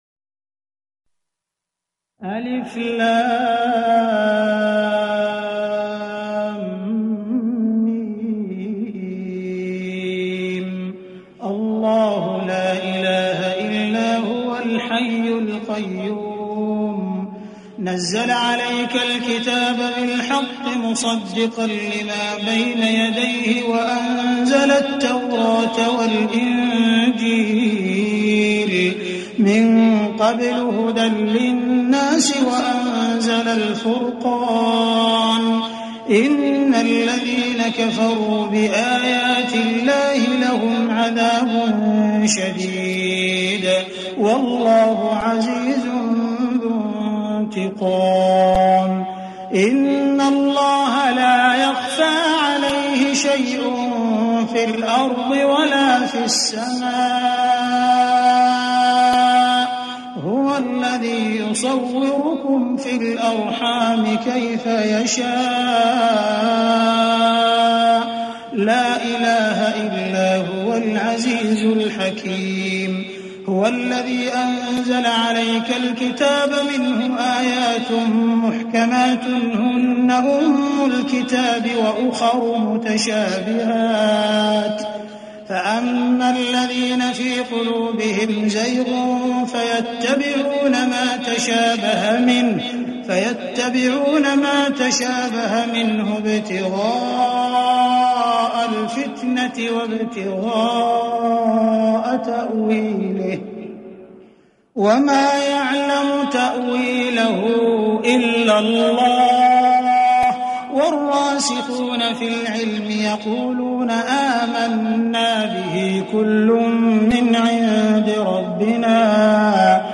Surah ALA E IMRAN – Quran Talawat by Imam-e-Kaaba Sheikh Abdul Rahman Al-Sudais
ﻋﺭﺑﻰ Arabic, Complete Quran Talawat (Recitation)